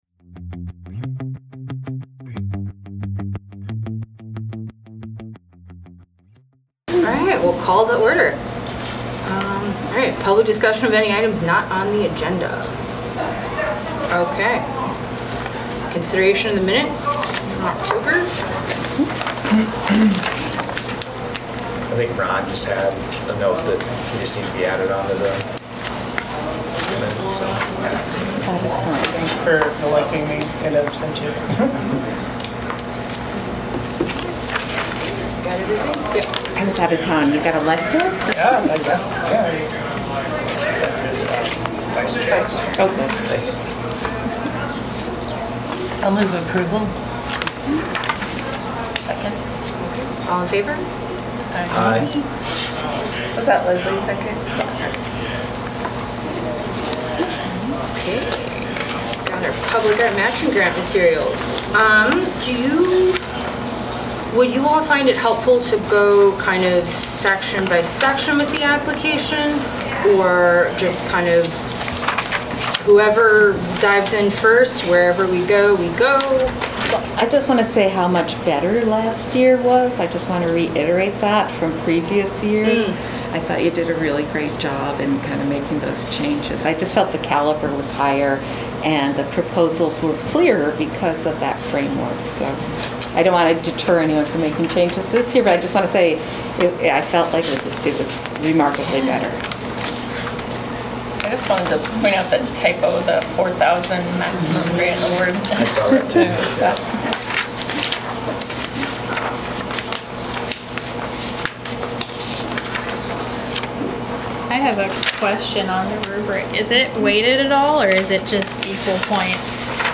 The regular monthly meeting of the Public Art Advisory Committee.